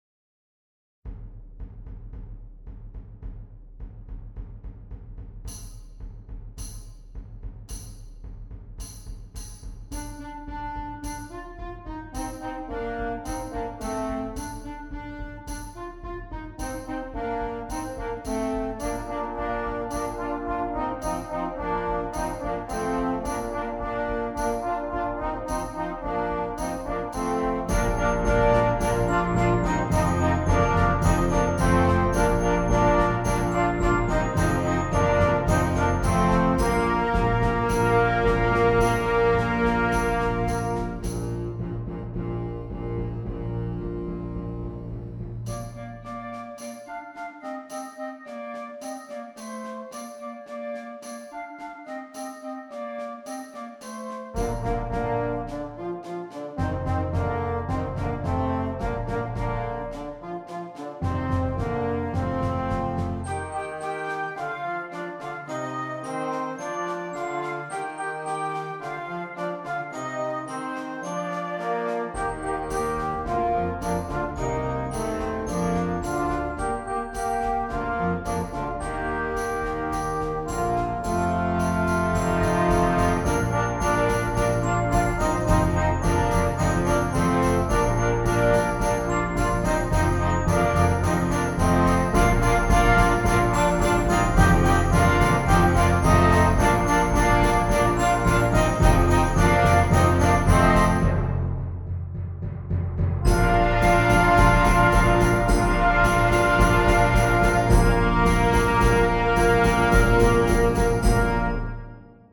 Concert Band
Traditional